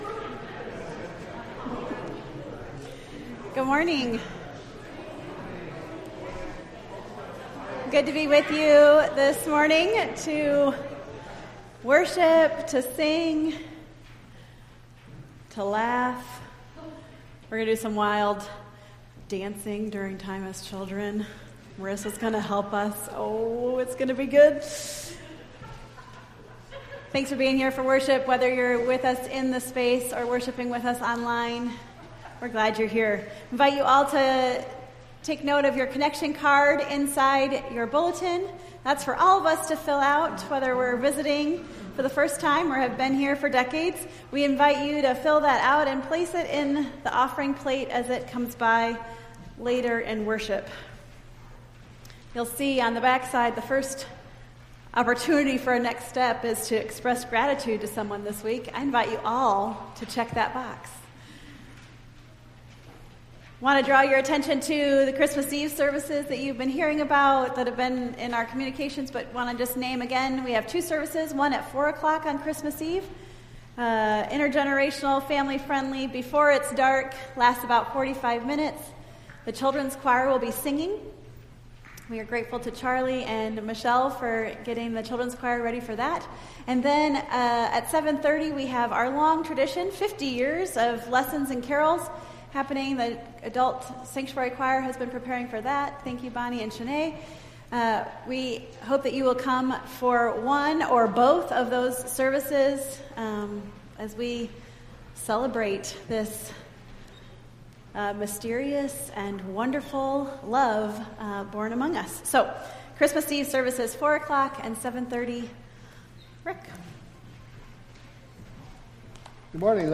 Entire December 15th Service